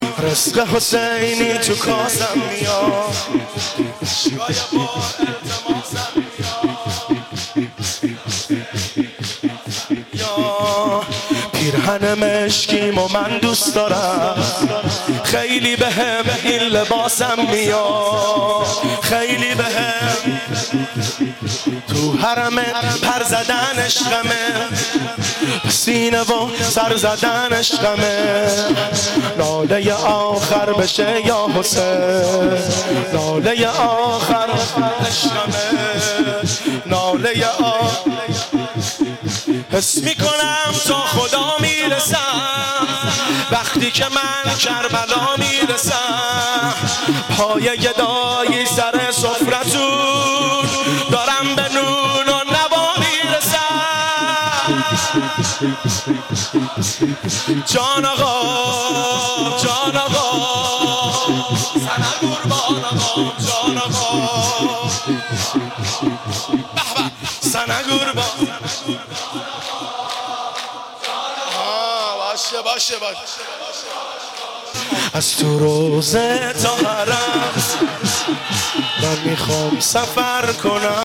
عید مبعث
شور